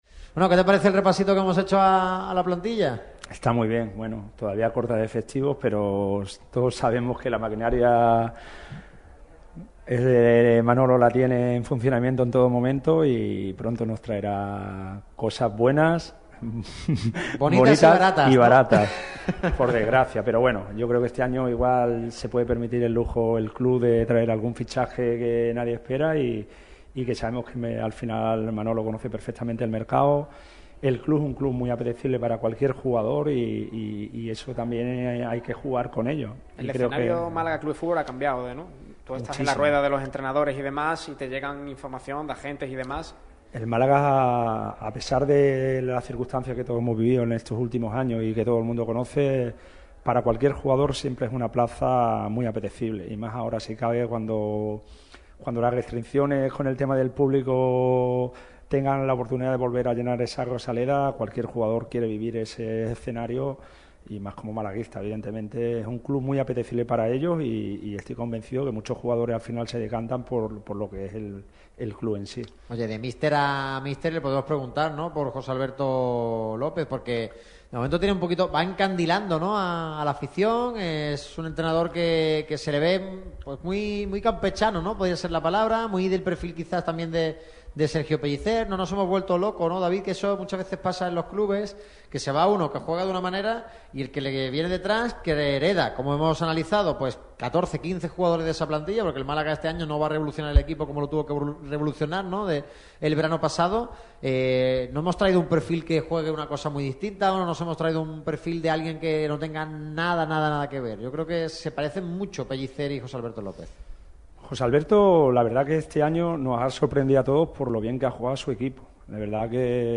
El que fuera técnico de las categorías inferiores del Málaga CF pasó por el micrófono rojo de Radio Marca Málaga para analizar la actualidad del equipo blanquiazul. Habló precisamente del mercado de fichajes.